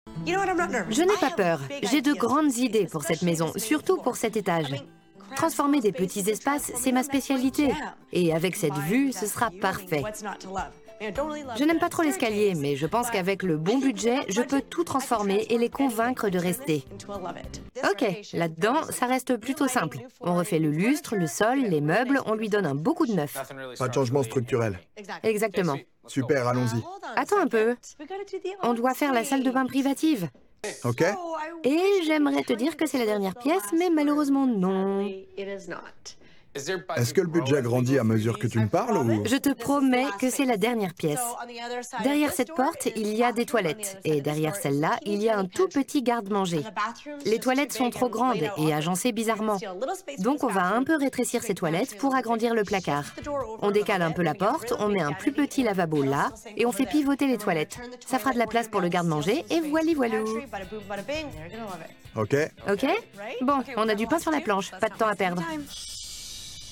Bandes-son
25 - 45 ans